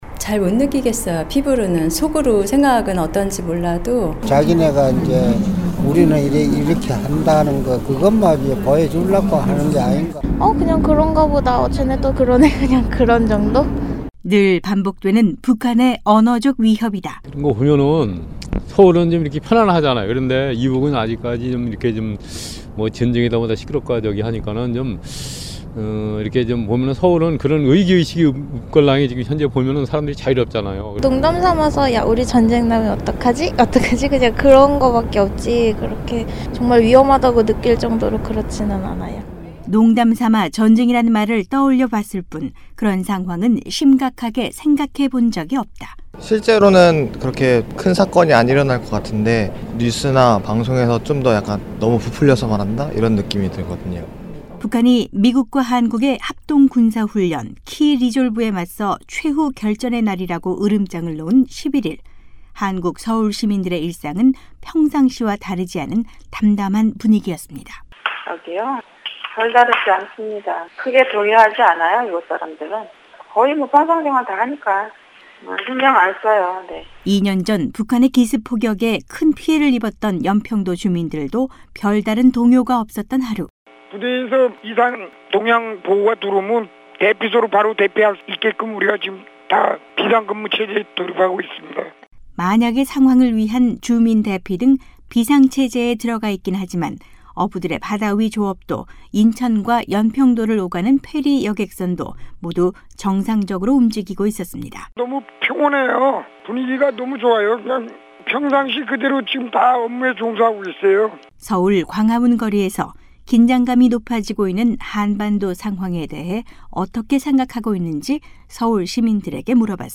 기자가 서울시민들을 만나봤습니다.